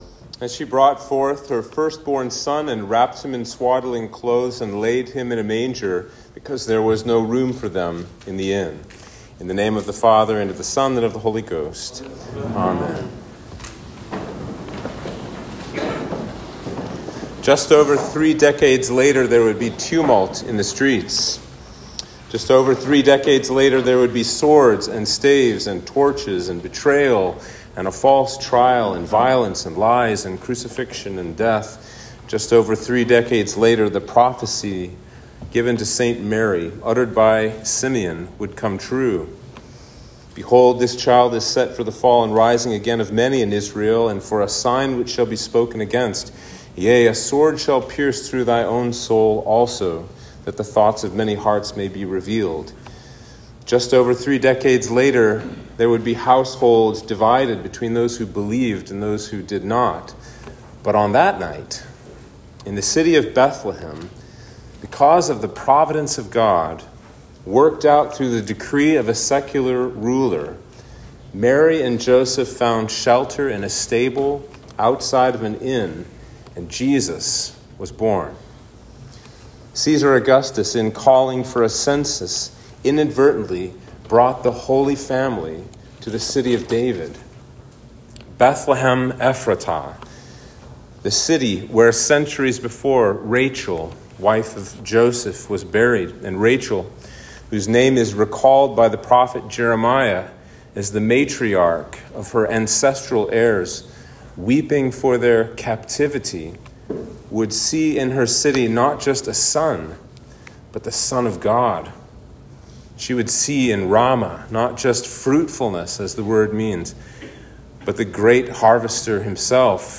Sermon for Christmas Eve